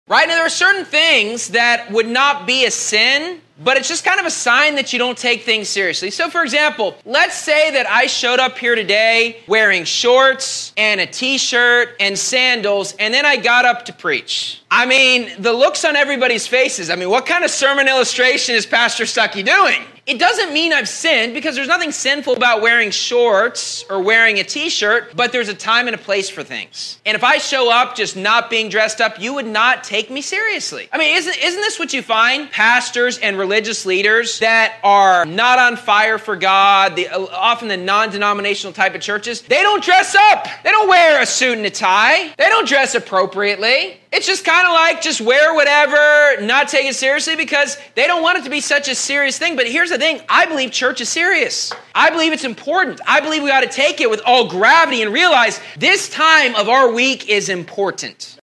Sermon Clips